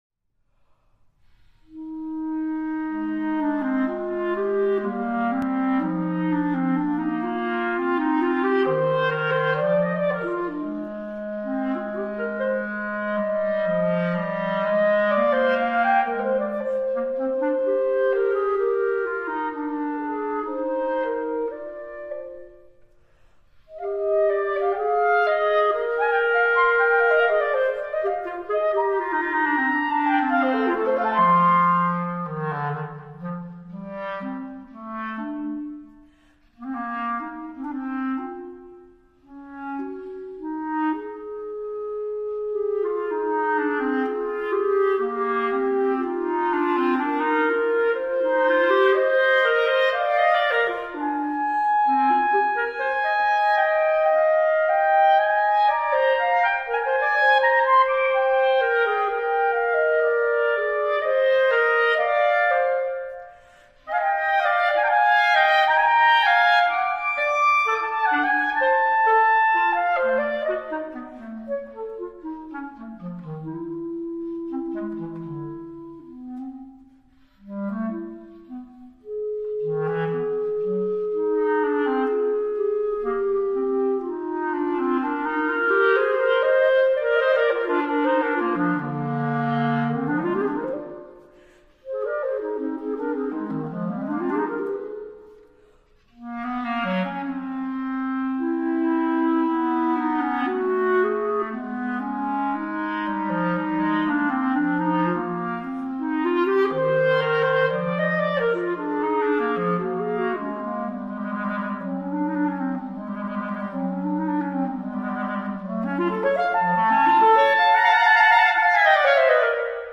Cl / Cl
单簧管二重奏
B♭ Clarinet 1 B♭ Clarinet 2